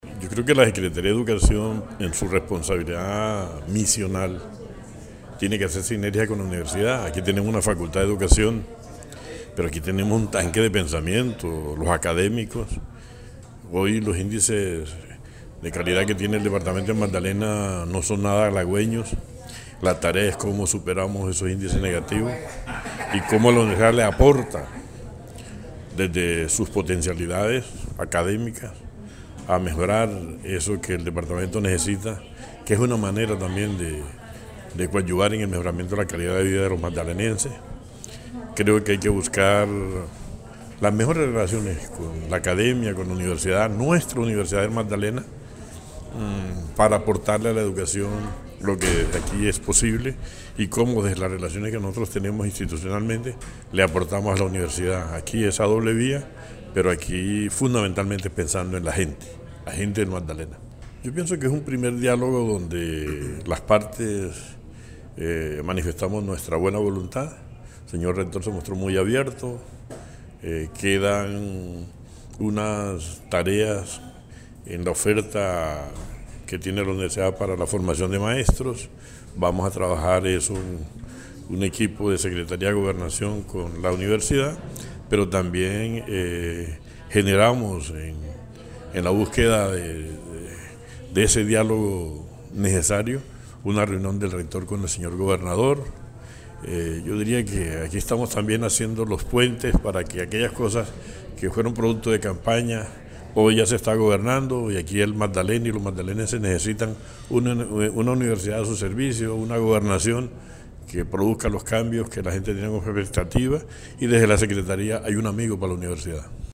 Secretario de Educación habla sobre la reunión con el rector Pablo Vera.
secretario_de_educacion_departamental_luis_grubert_1.mp3